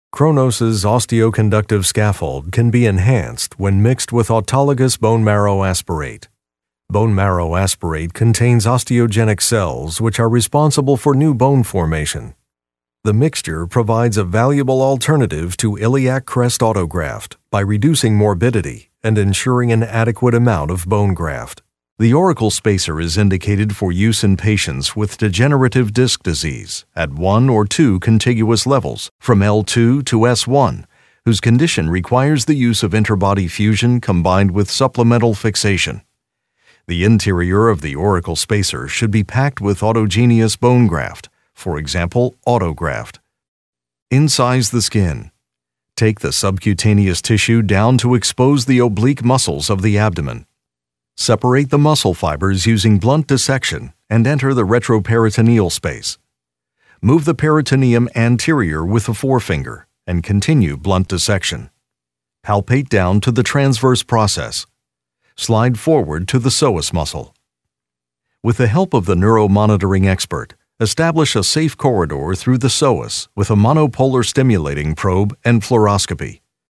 Veteran VO talent with warm, authoritative, clear and convincing voice.
Medical Narration
My voice is best described as warm, articulate, friendly and authoritative.